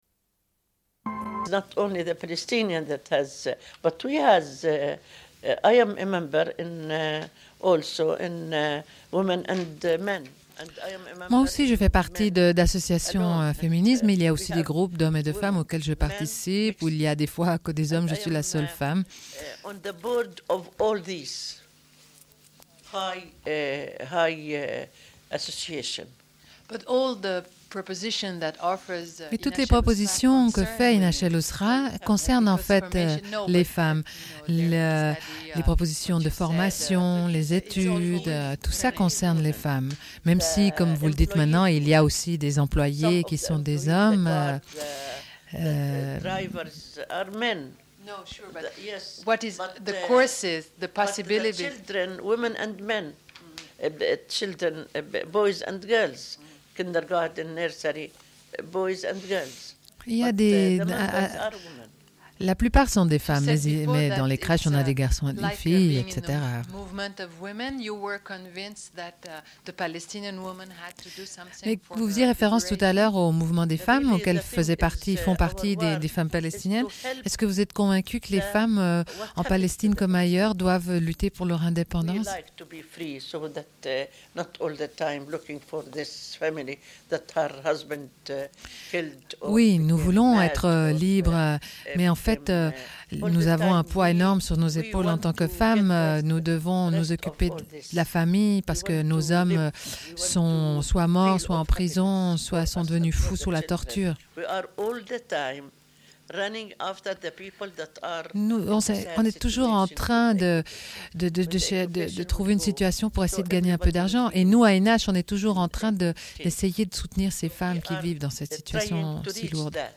Une cassette audio, face A31:27